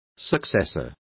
Complete with pronunciation of the translation of successor.